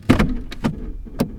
GearShifting1.WAV